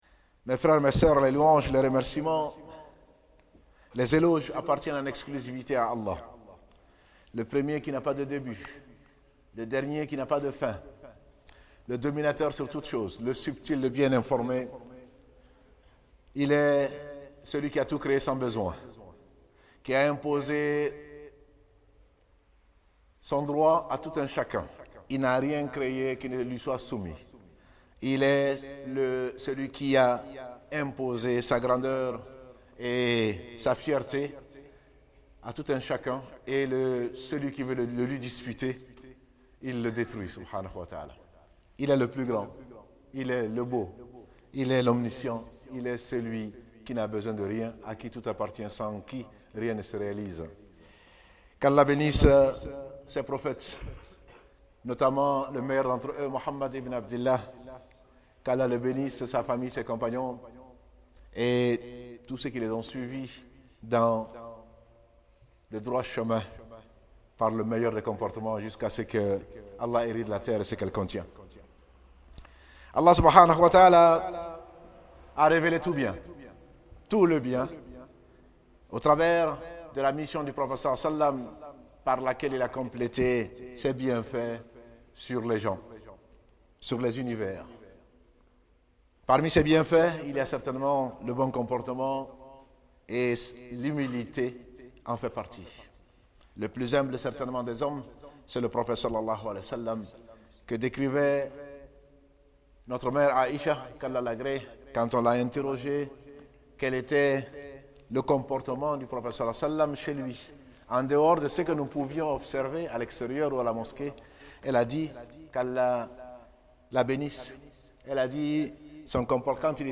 (Djoumu'a du 14/08/2015)